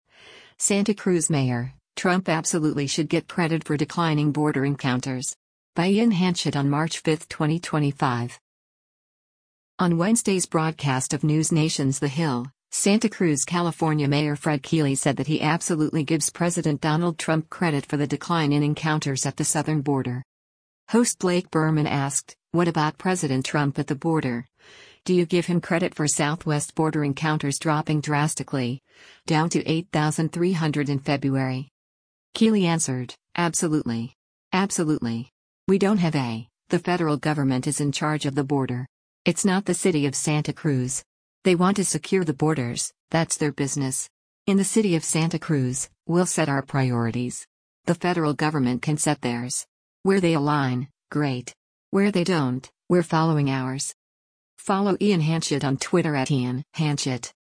On Wednesday’s broadcast of NewsNation’s “The Hill,” Santa Cruz, CA Mayor Fred Keeley said that he “Absolutely” gives President Donald Trump credit for the decline in encounters at the southern border.